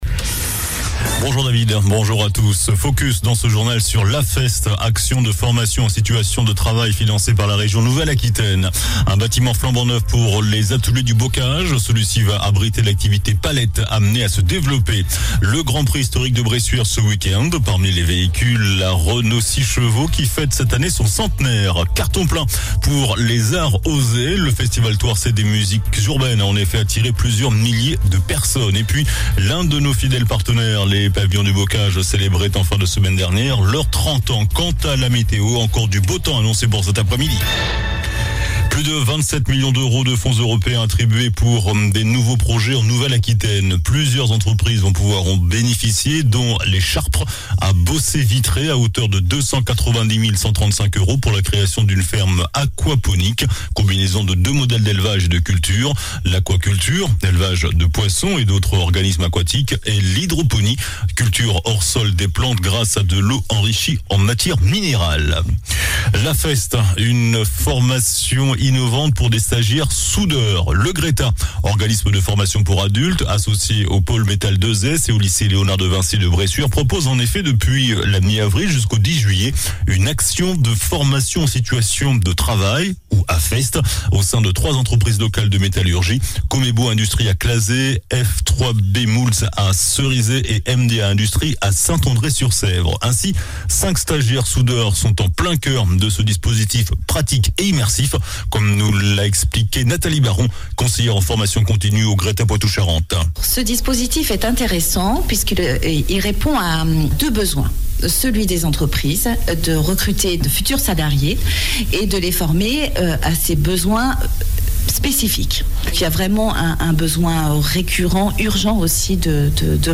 JOURNAL DU LUNDI 26 JUIN ( MIDI )